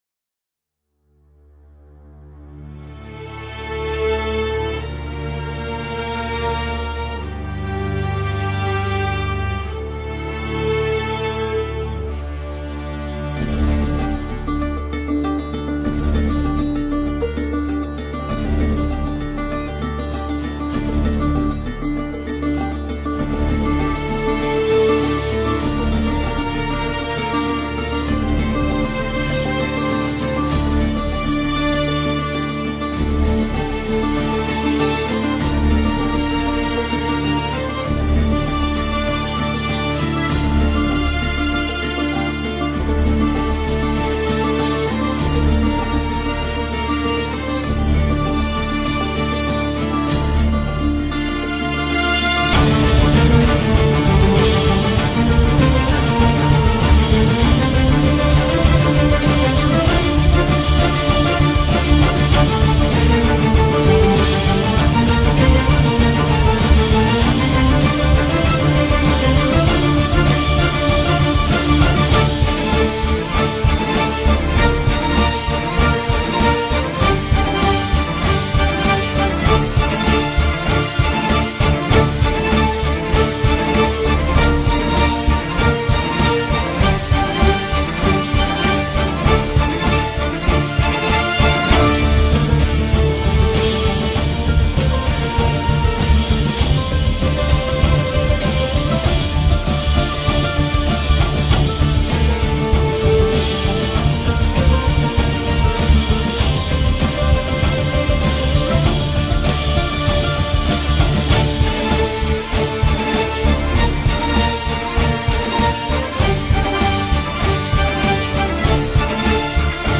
tema dizi müziği, duygusal hüzünlü heyecan fon müzik.